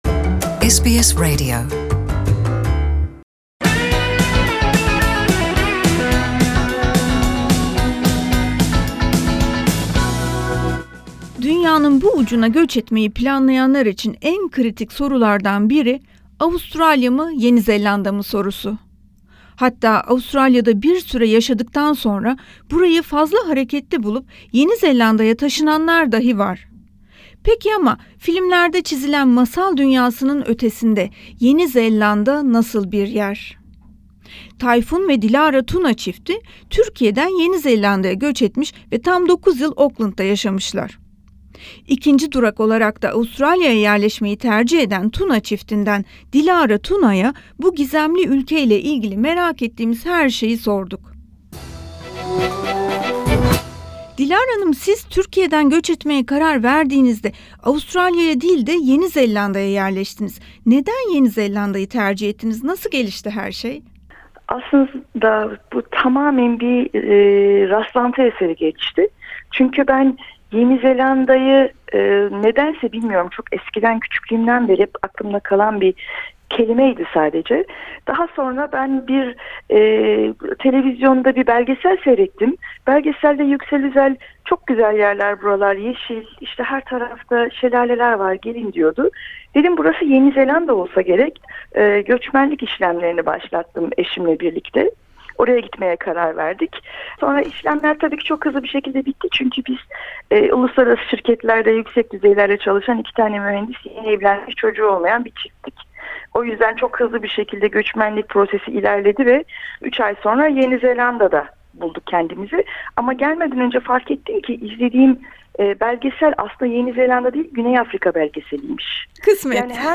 röportajımızda ayrıca Yeni Zelanda'ya göç etmek isteyenlere ip uçları da verdi.